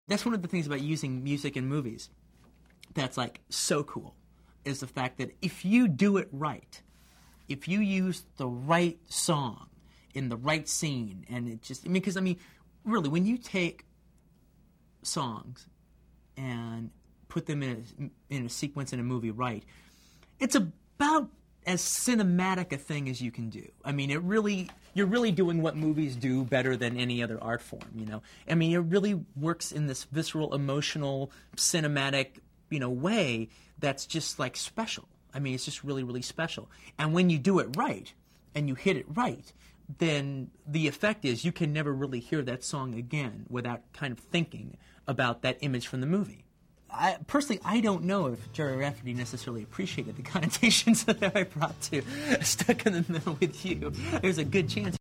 InterviewQuentinTarantino.mp3